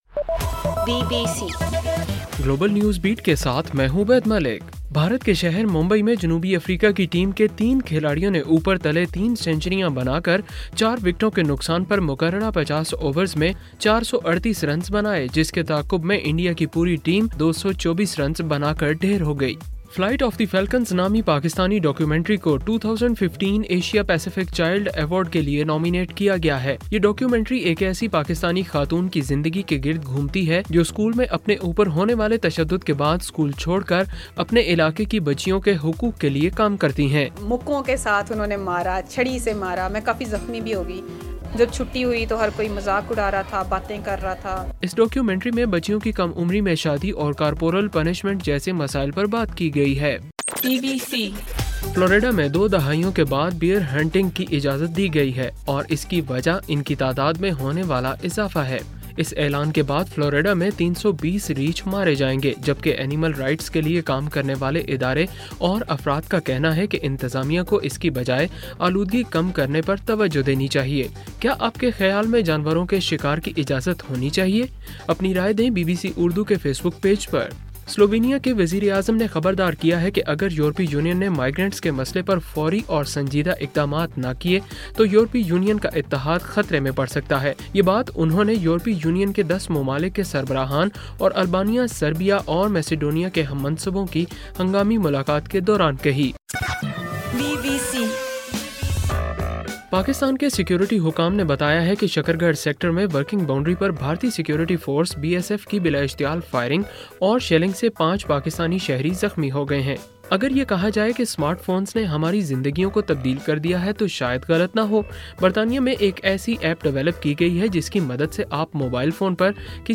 اکتوبر 26: صبح 1 بجے کا گلوبل نیوز بیٹ بُلیٹن